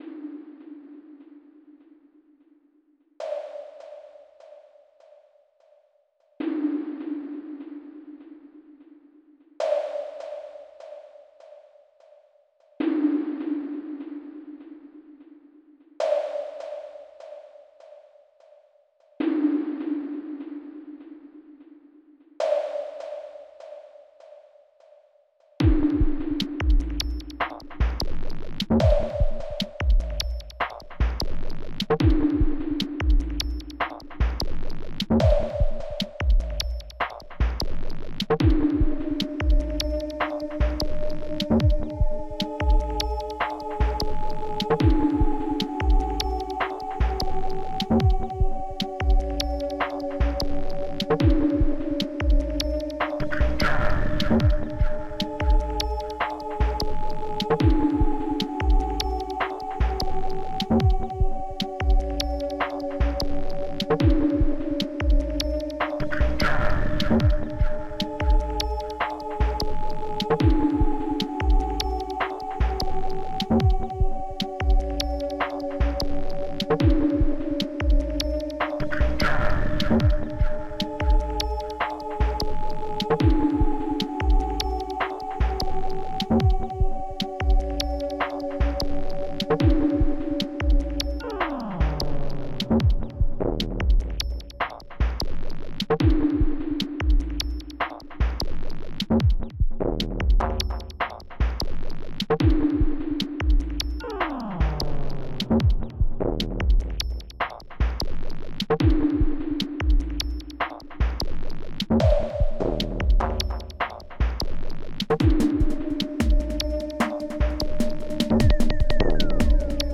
xm (FastTracker 2 v1.04)
All the drum/percus.
Nordlead synth, and
~~~ choir-sample...
The deep growling